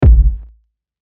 Sample 7 — Bang (kick)
Sample-4-Bang-Kick.mp3